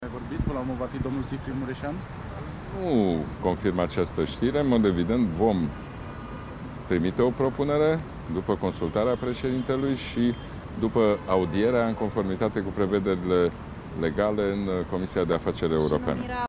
Pe de altă parte, întrebat de jurnaliști Ludovic Orban a refuzat pentru moment să confirme desemnarea lui Siegfried Mureșan.